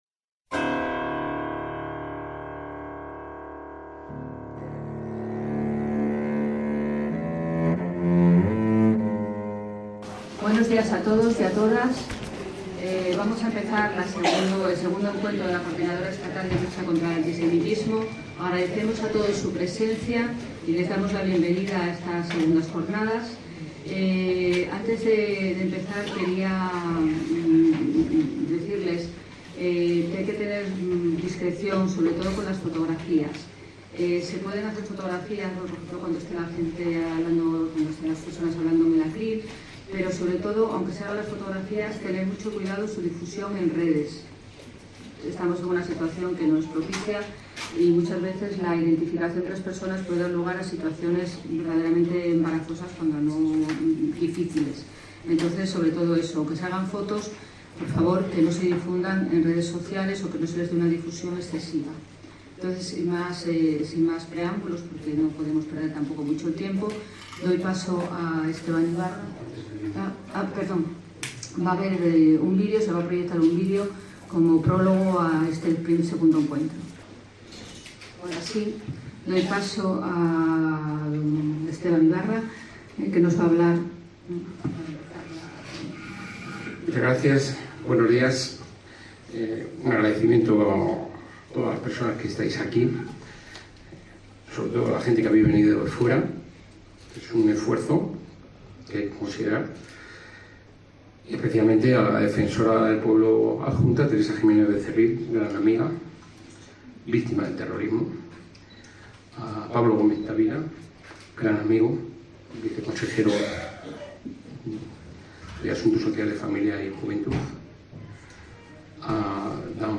DE ACTUALIDAD - El viernes 12 de diciembre de 2025 tuvo lugar en Madrid el segundo encuentro de la Coodinadora Estatal de Lucha Contra el Antisemitismo.